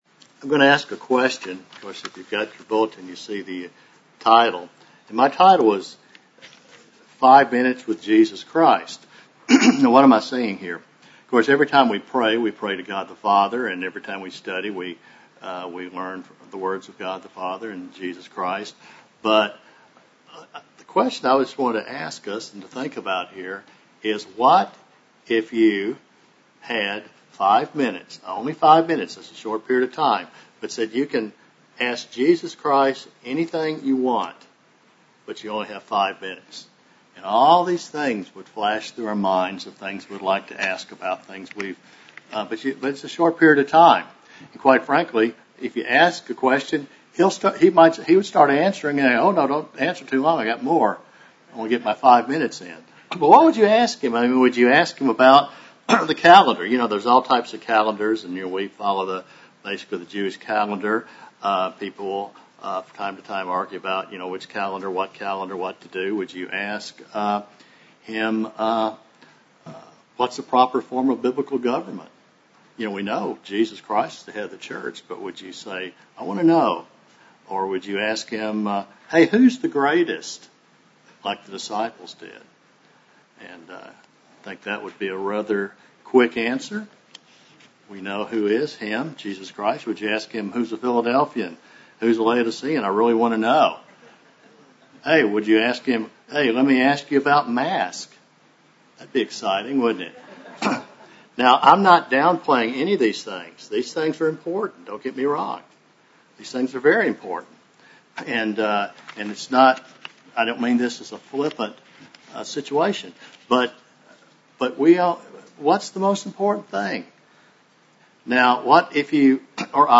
Sermons
Given in Dallas, TX Fort Worth, TX